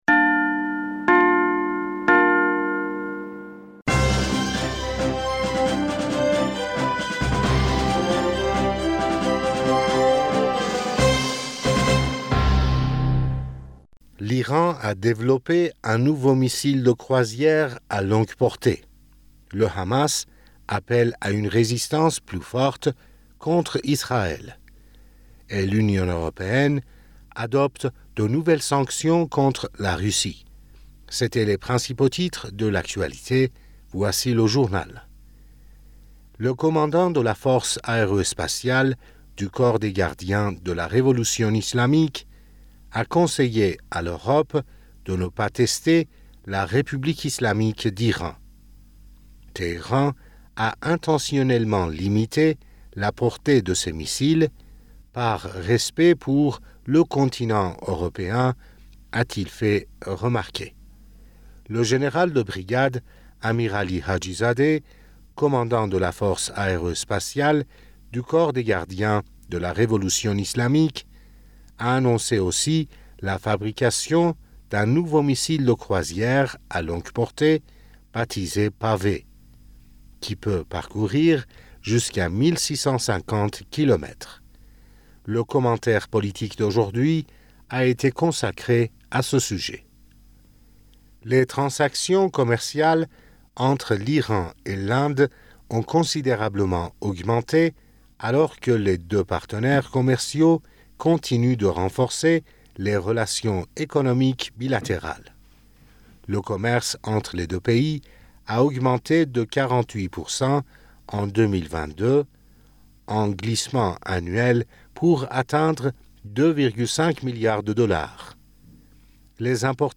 Bulletin d'information du 25 Février